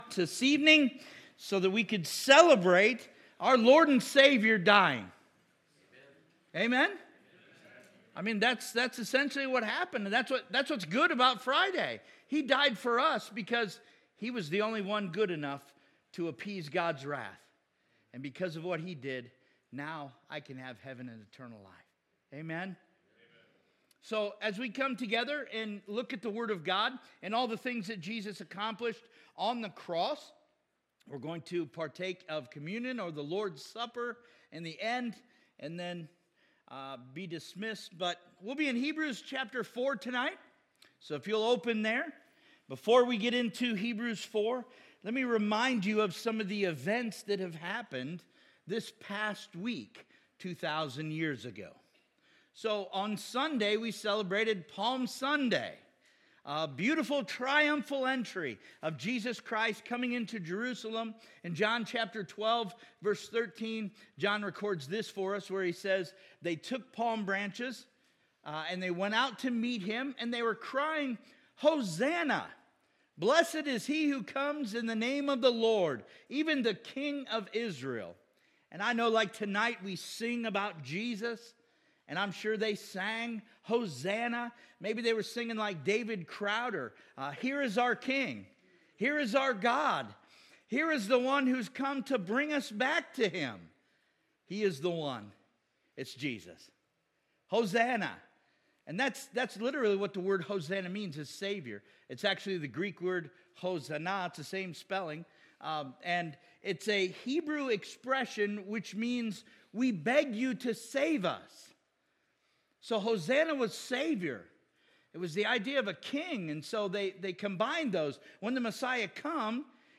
Sermons Archive - Page 7 of 51 -